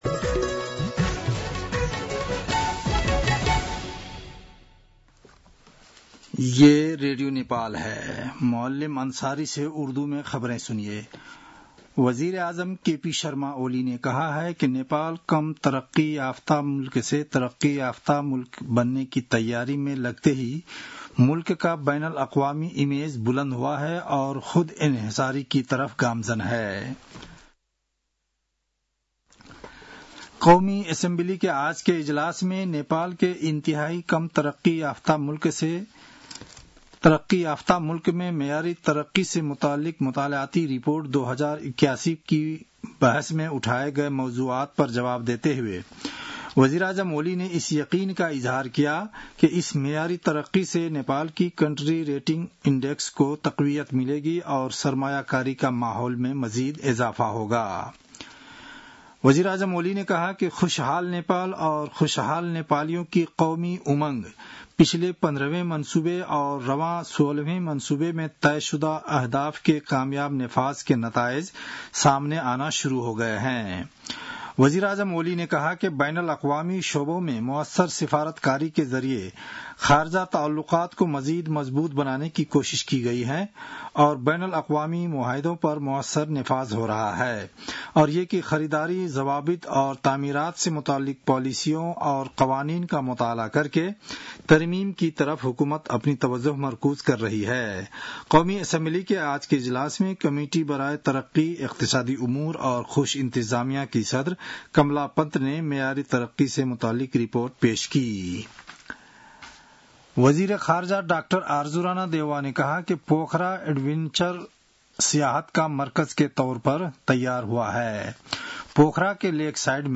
उर्दु भाषामा समाचार : १४ साउन , २०८२
Urdu-news-4-14.mp3